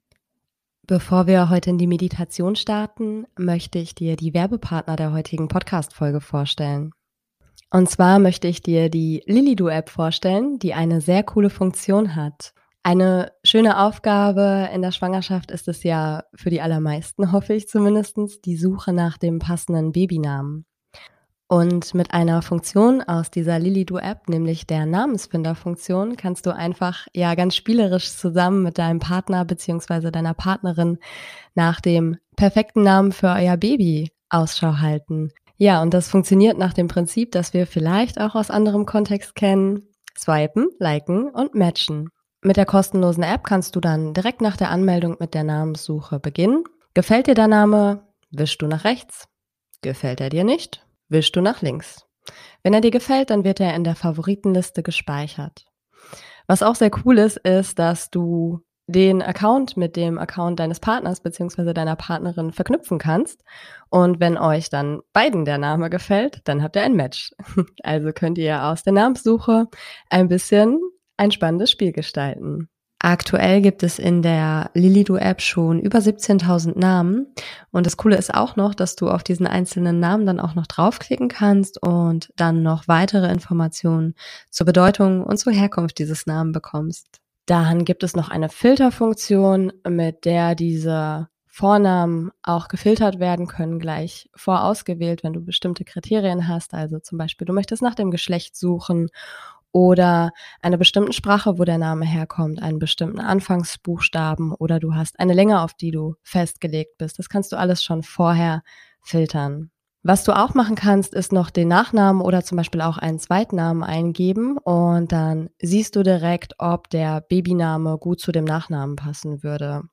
#085 - Entspannungsmeditation - Duftreise [Für Schwangere] ~ Meditationen für die Schwangerschaft und Geburt - mama.namaste Podcast
Am Ende der Meditation gibt es noch eine kurze Phase der Stille, die zum ganz Entspannen und wirken lassen gedacht ist.